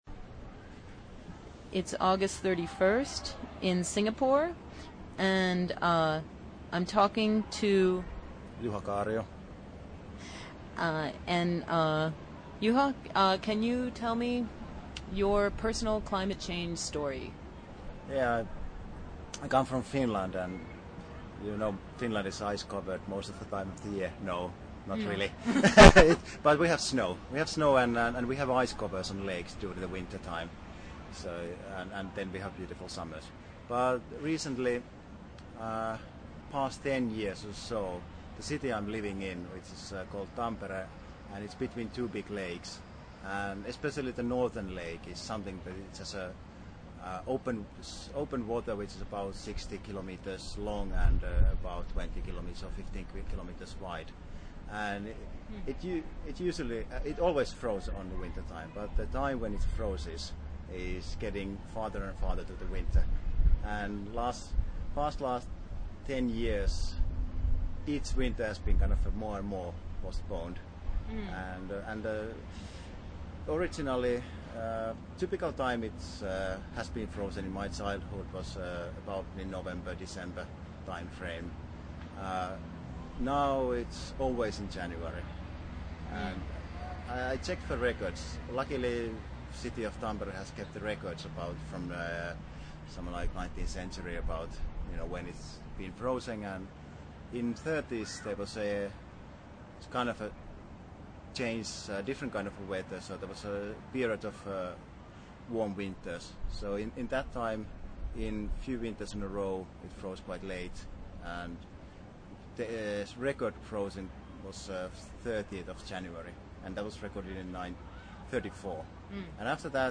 Personal Climate Change Stories, recorded in Singapore
During the Luminous Green workshop at Singapore Management University during the ISEA festival, I was able to conduct short interviews with 5 of the international participants about their personal experiences with climate change. The range of backgrounds and experiences was very wide, from the Malaysian jungle to the north of Finland.
Apologies for the noise in the background of the recordings, but perhaps enjoy the Singapore street sounds.